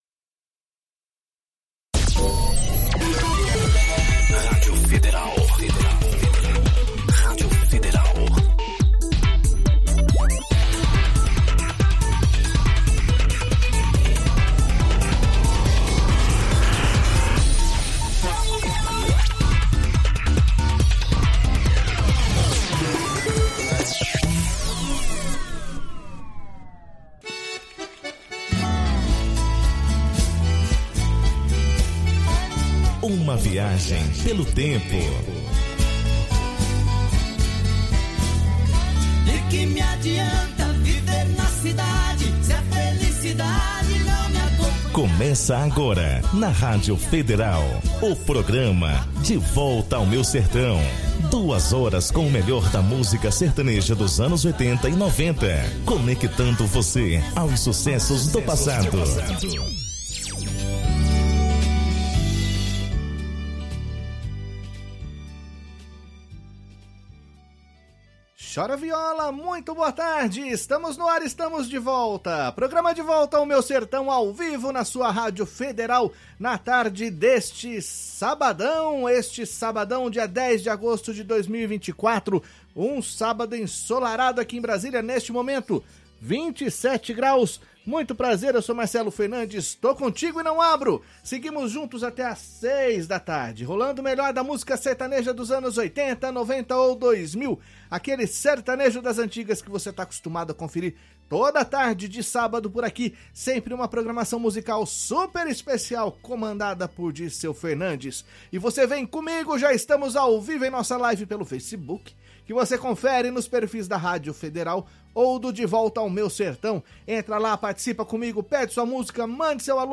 “De volta ao Meu Sertão” te leva a uma viagem pelo universo da música sertaneja